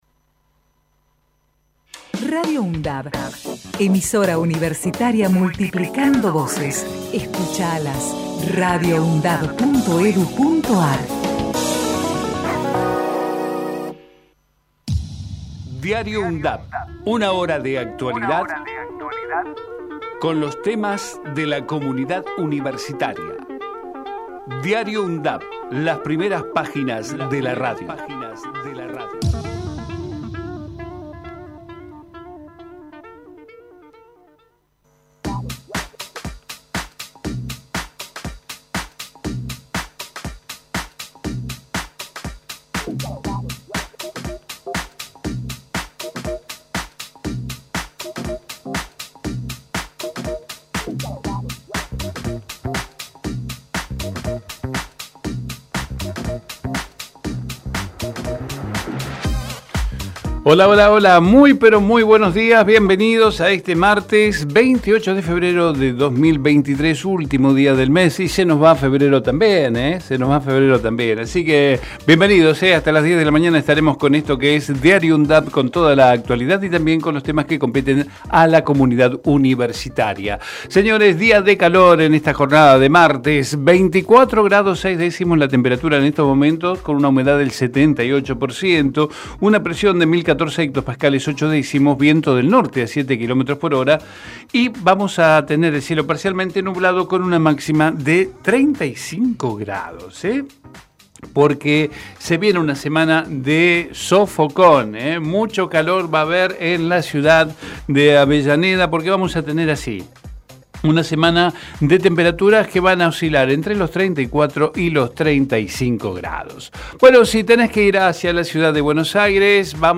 Diario UNDAV Texto de la nota: Nuevo ciclo 2023. De lunes a viernes de 9 a 10 realizamos un repaso por la actualidad universitaria en las voces de los protagonistas, testimonios de quienes forman parte de la UNDAV. Investigamos la historia de las Universidades Nacionales de todo el país y compartimos entrevistas realizadas a referentes sociales, culturales y académicos.